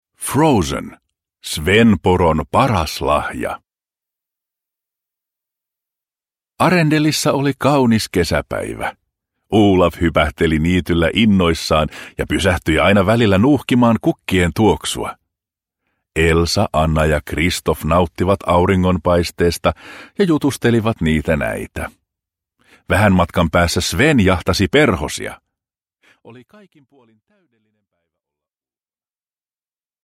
Frozen. Sven-poron paras lahja – Ljudbok – Laddas ner